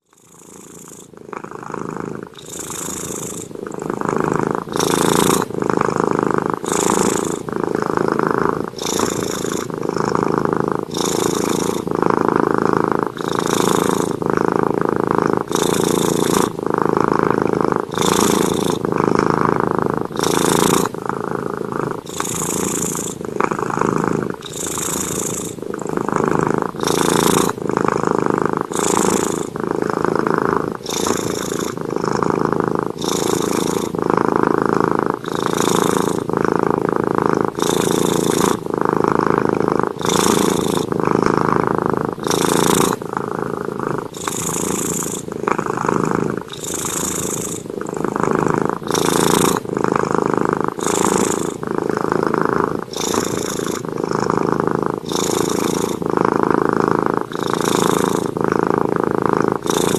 Purring Cat Sound Effects sound effects free download